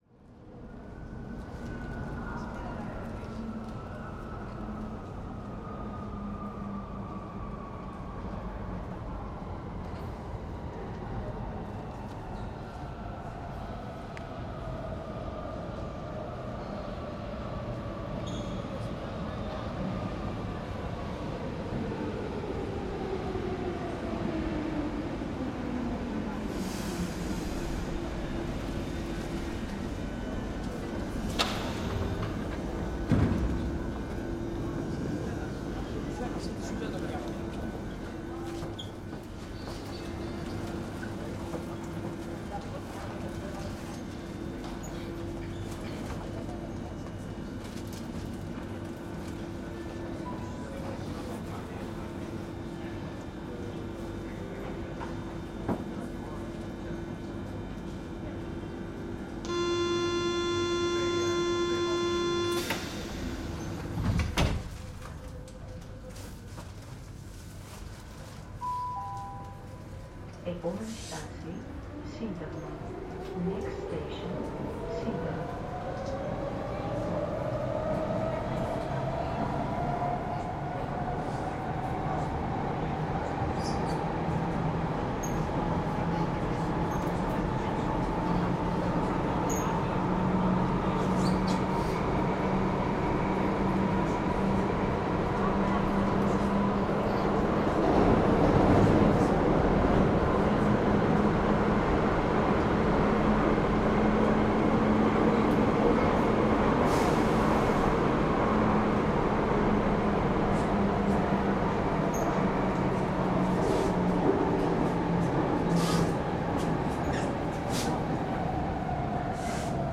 French Train Station Ambience 01
train-station-ambience-01.mp3